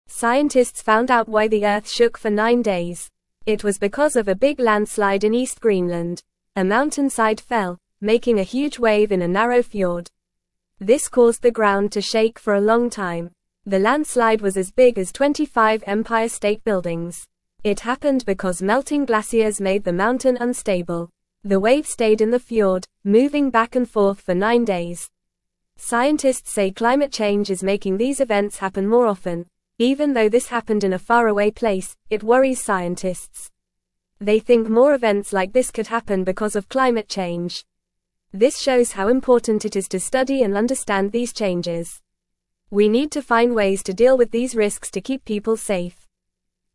Fast
English-Newsroom-Lower-Intermediate-FAST-Reading-Big-Wave-Shook-Earth-for-Nine-Days-Straight.mp3